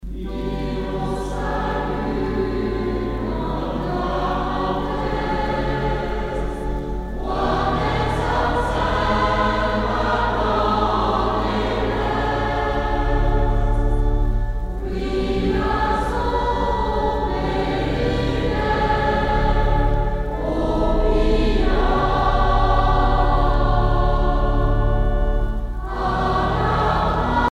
circonstance : dévotion, religion ;
Pièce musicale éditée